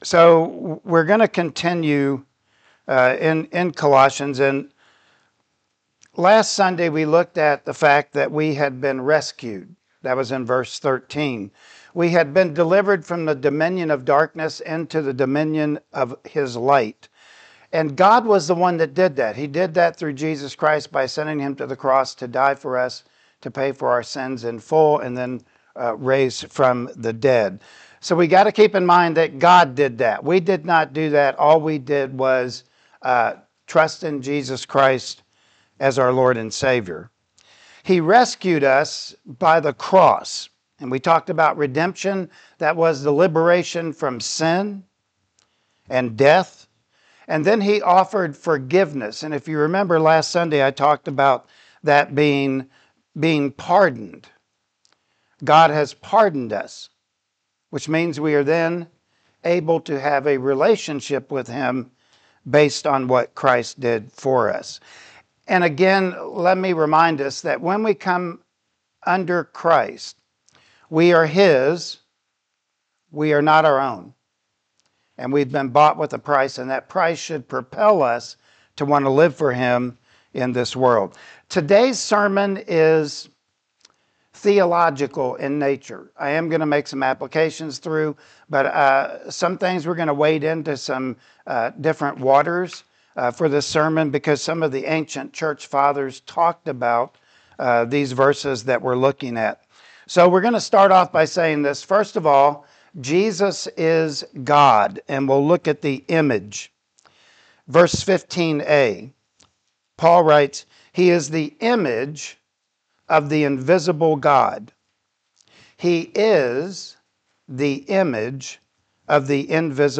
Colossians 1:15-16 Service Type: Sunday Morning Worship Service Topics: Jesus is God